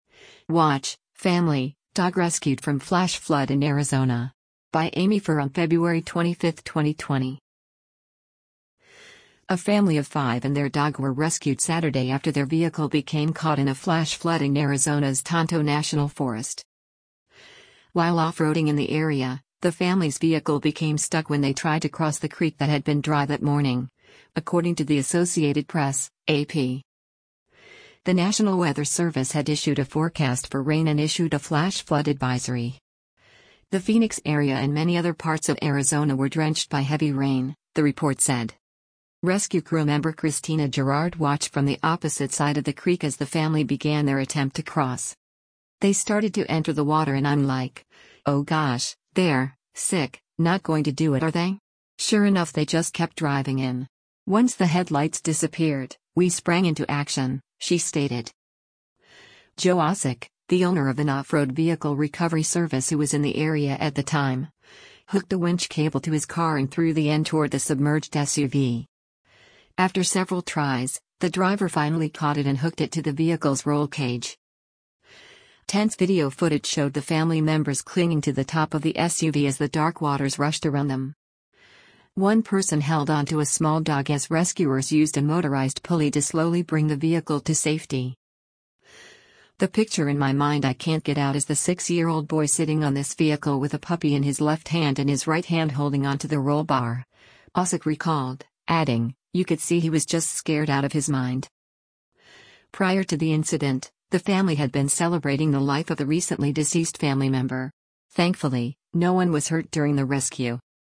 Tense video footage showed the family members clinging to the top of the SUV as the dark waters rushed around them.